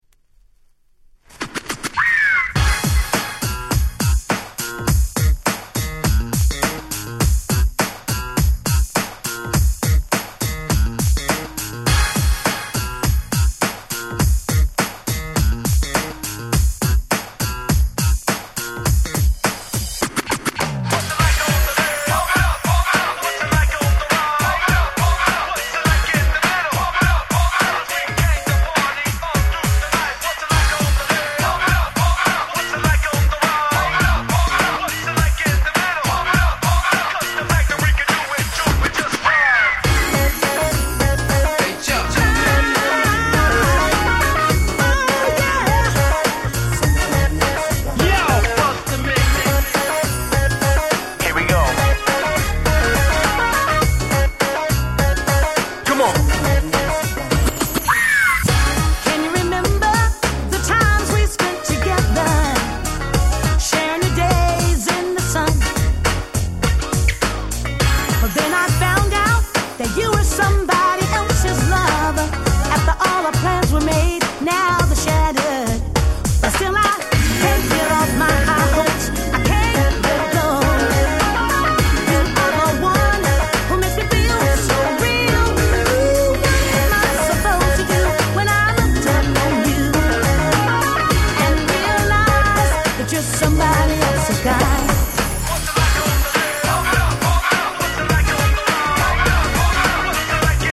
Super Dance Classics !!